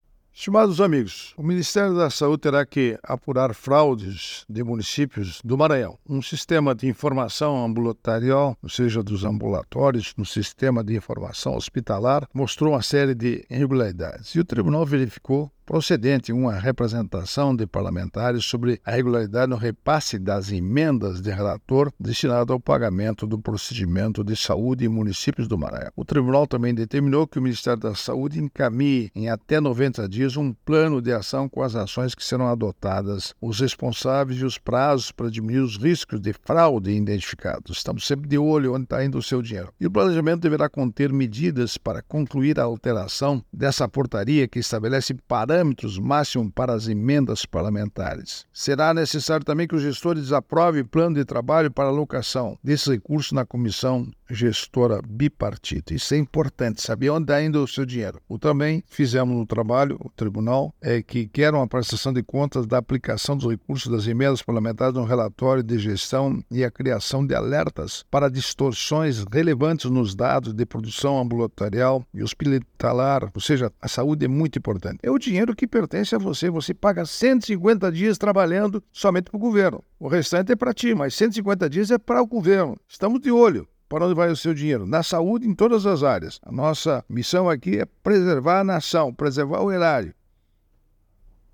É o assunto do comentário desta segunda-feira (09/09/24) do ministro Augusto Nardes (TCU), especialmente para OgazeteitO.